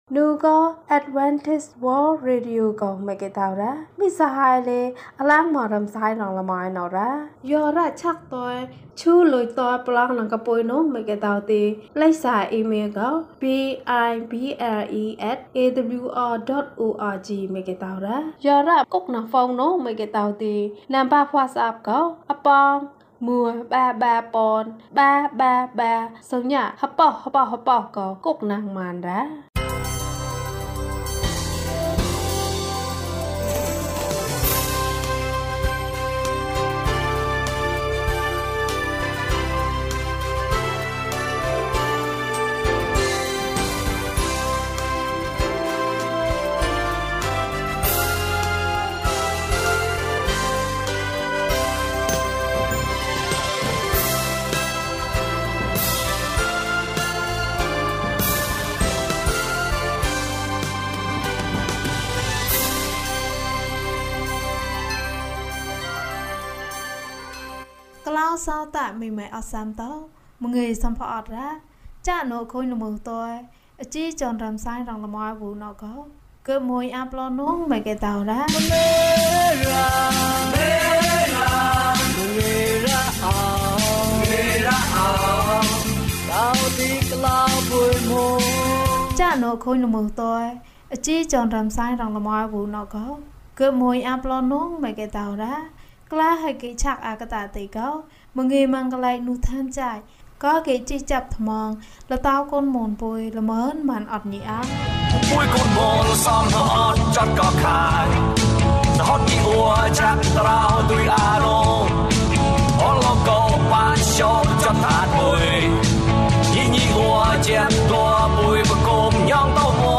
သူသည် ကျွန်ုပ်ကို အချိန်တိုင်း ကယ်တင်သည်။ ကျန်းမာခြင်းအကြောင်းအရာ။ ဓမ္မသီချင်း။ တရားဒေသနာ။